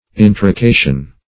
Intrication \In`tri*ca"tion\, n.